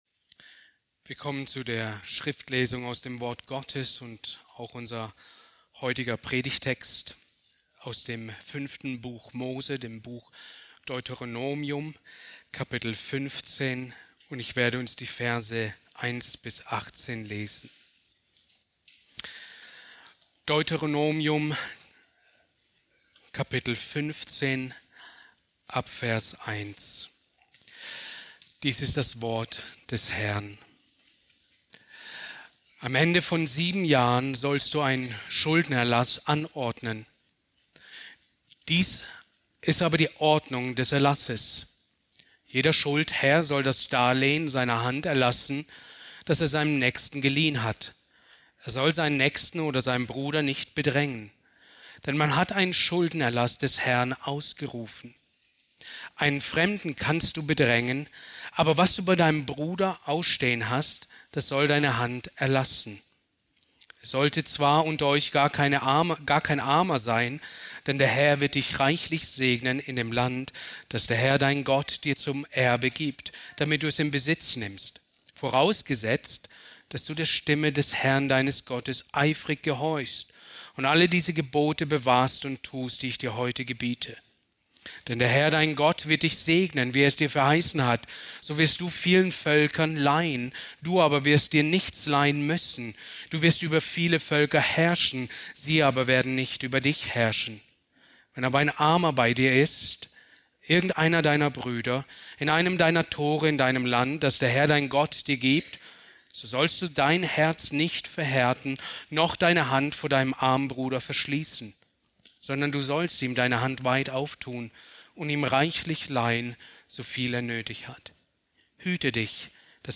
Predigt aus der Serie: "Christologie"